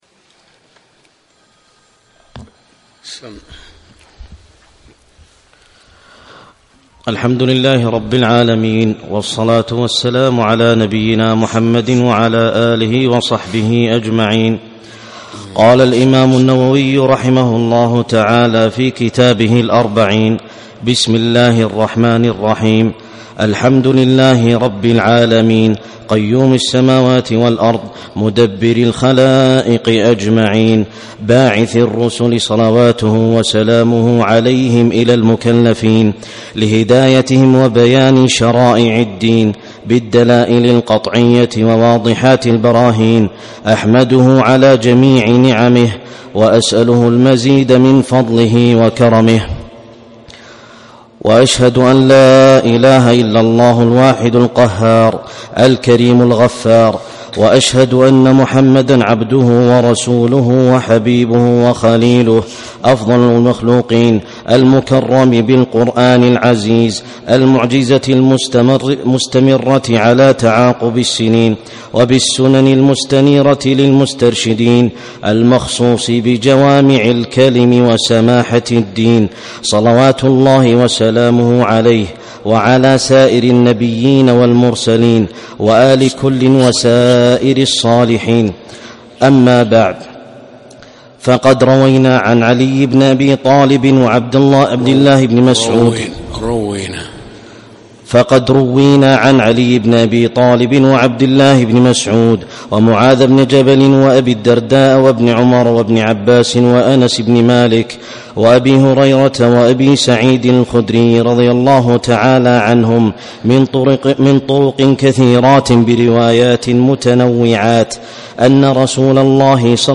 يوم الأربعاء 15 جمادى الأولى 1437 في مسجد الشيخ
الدرس الأول